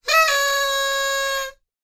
whistle_party_noise_maker_005